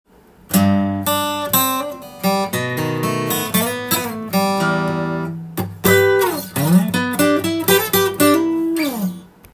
フレット交換後
音も違い過ぎてビックリです！
フレット交換後は、音に元気が宿っています。低音も高音も良く出ています。